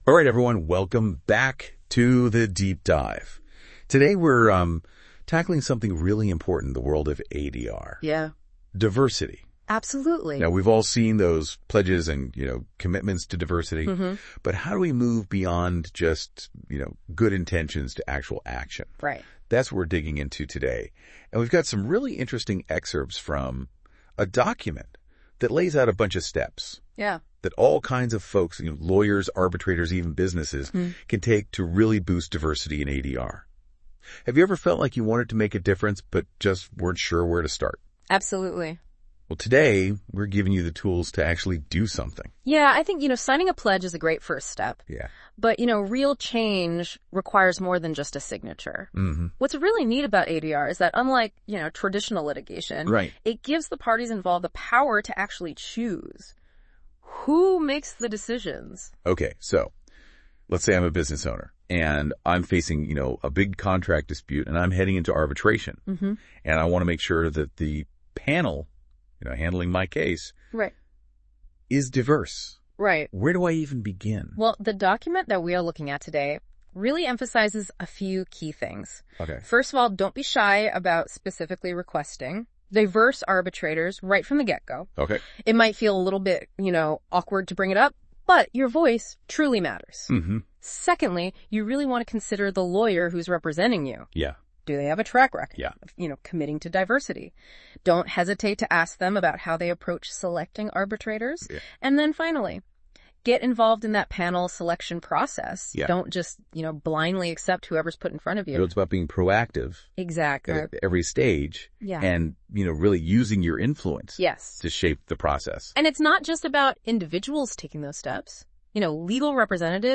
Experience this article as a podcast episode with Google NotebookLM’s innovative Audio Overview feature. This tool transforms written content into dynamic audio discussions, where AI hosts summarize key insights, link related topics and create an engaging conversation.
Click here to listen to the AI-generated podcast summary of this article